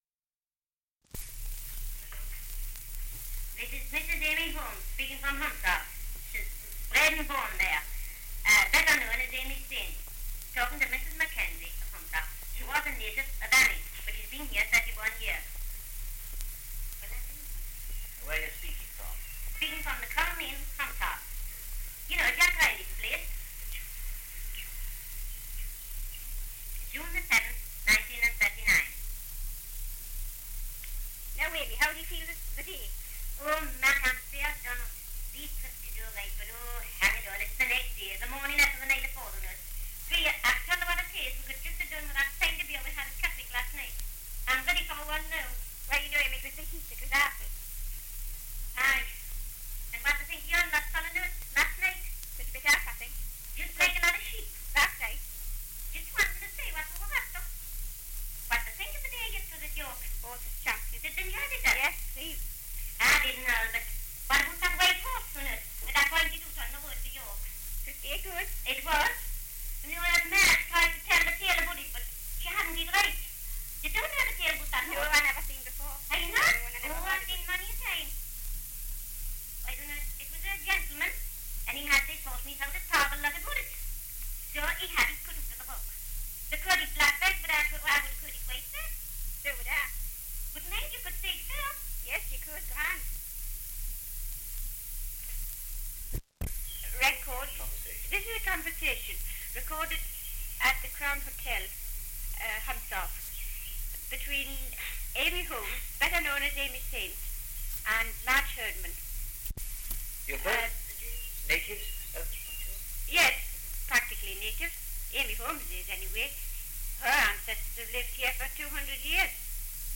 2 - Dialect recording in Humshaugh, Northumberland
78 r.p.m., cellulose nitrate on aluminium